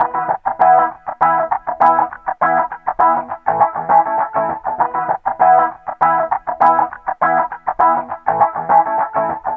Loops guitares rythmique- 100bpm 3
Guitare rythmique 49